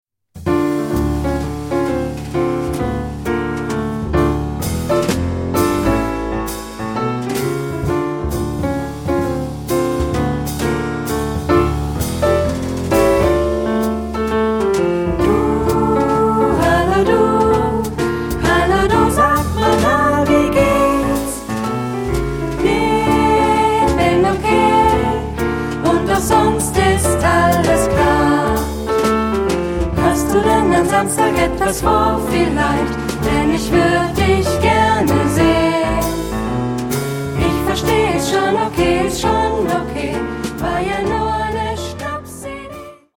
Ad libitum (3 Ad libitum Stimmen).
Kanon. Choraljazz.
jazzy ; fröhlich ; leicht
Tonart(en): d-moll